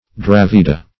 Search Result for " dravida" : The Collaborative International Dictionary of English v.0.48: Dravida \Dra"vi*da\, n. pl.